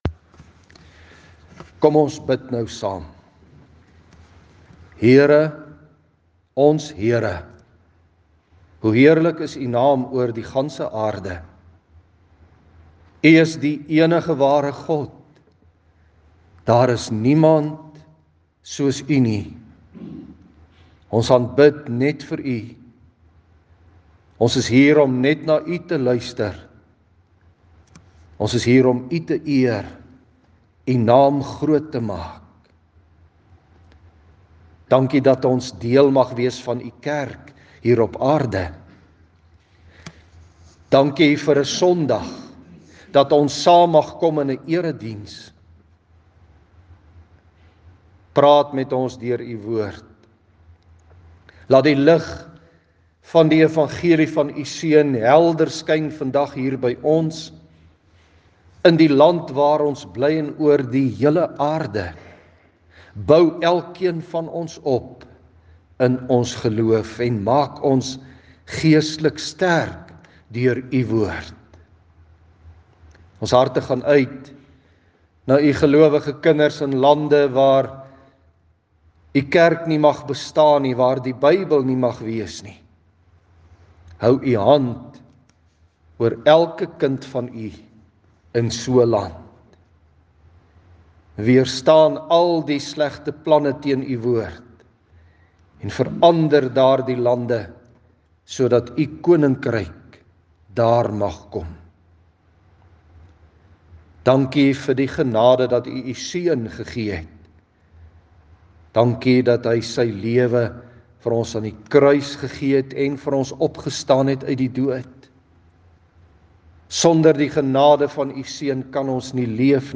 Inleiding: Ons het vanoggend die genade om God se Woord te kan hoor en die doop van ‘n seuntjie te kan beleef.